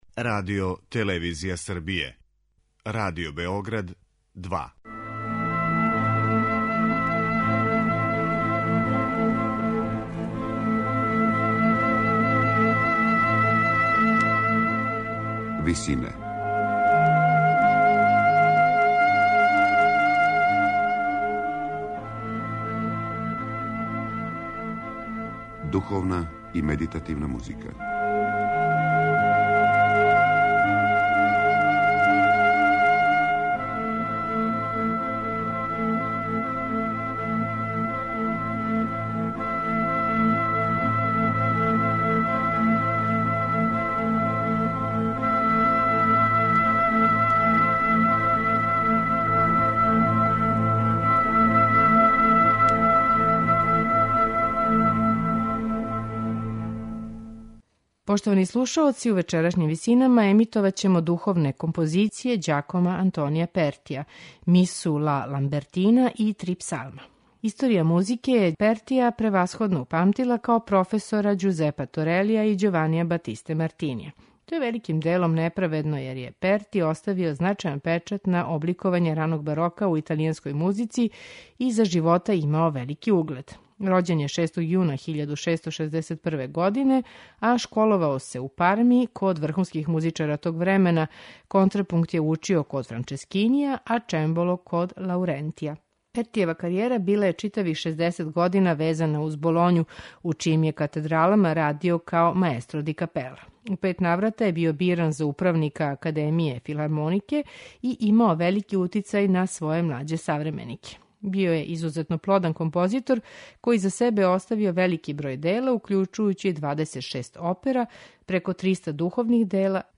Пертијеву музику карактеришу богата мелодијска инвенција, употреба маштовитих хармонских решења и колоритна оркестрација.
сопран
алт
бас